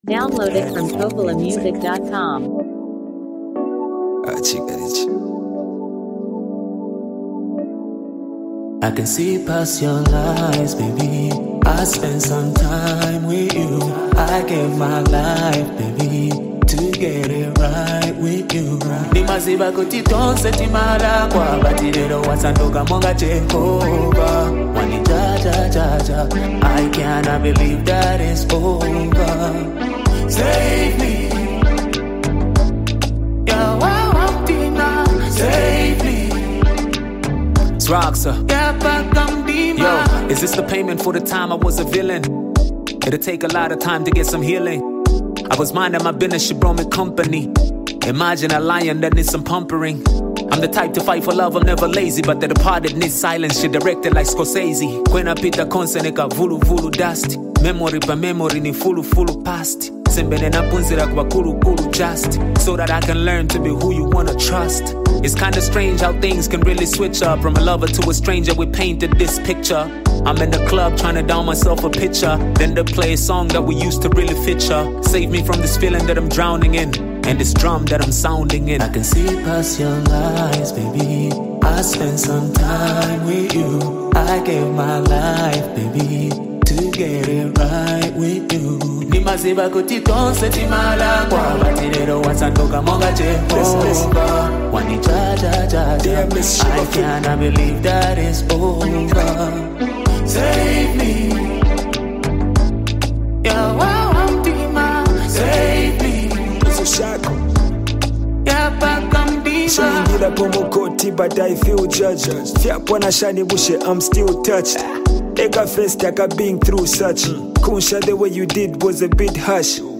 a deeply emotional and reflective song